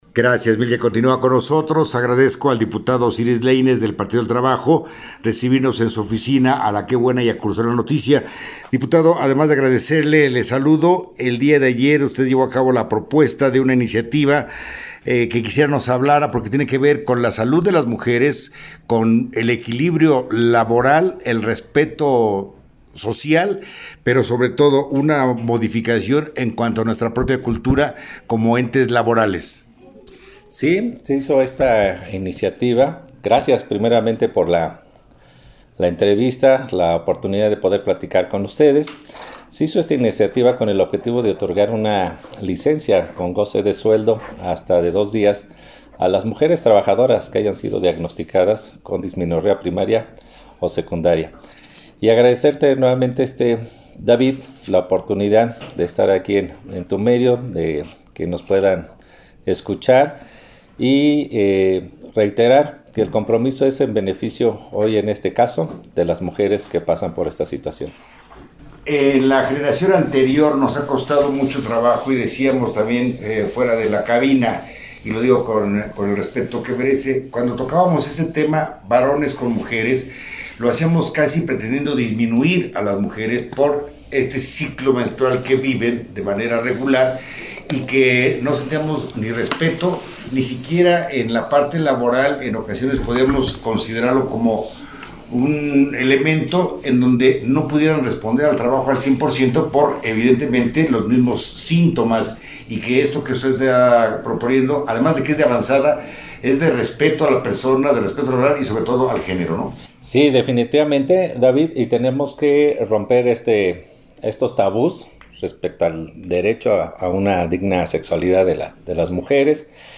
Entrevista
El diputado Osiris Leines, del PT Hidalgo, en entrevista comenta los puntos sustantivos de su iniciativa en la cual se propone reformar la Ley de los Trabajadores al servicio de los gobiernos estatales  y municipales con el objetivo de otorgar una licencia a mujeres en periodo menstrual